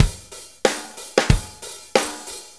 Shuffle Loop 28-05.wav